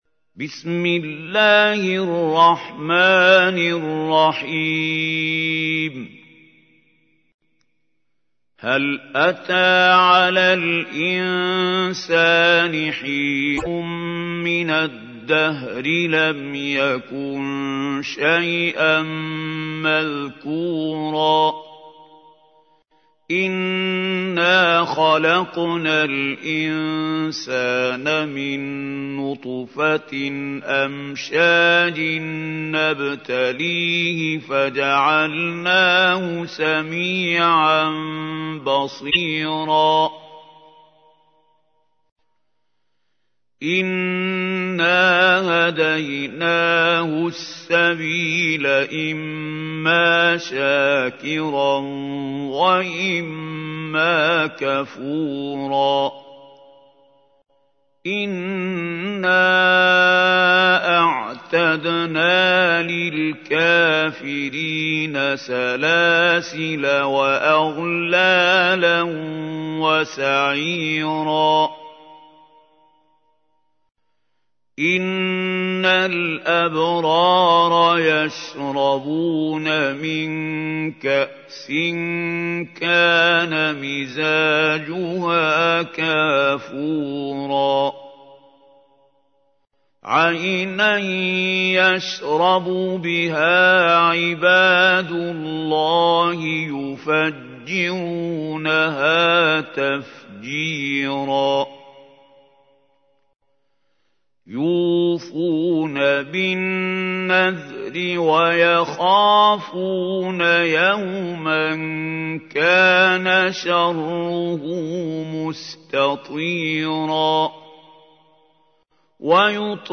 تحميل : 76. سورة الإنسان / القارئ محمود خليل الحصري / القرآن الكريم / موقع يا حسين